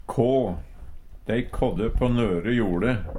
Høyr på uttala Ordklasse: Verb Kategori: Jordbruk og seterbruk Attende til søk